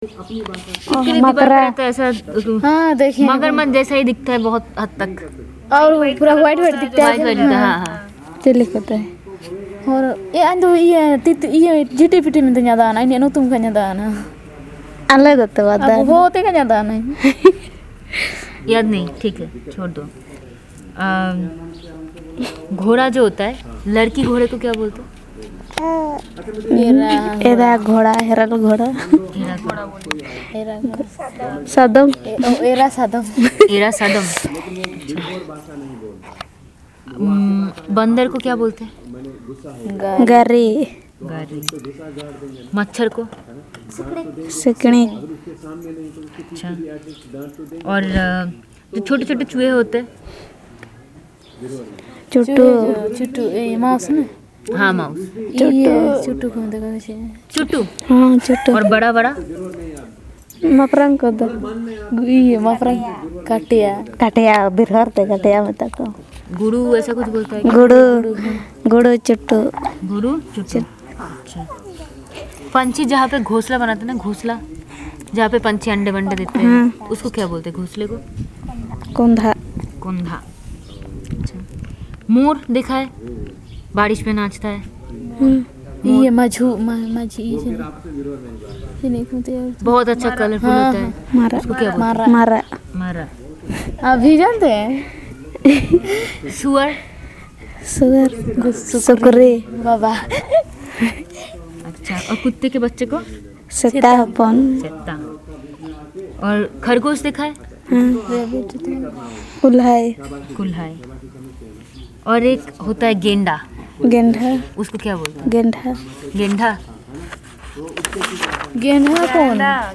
Elicitation of words related to wild animals known to the Birhor community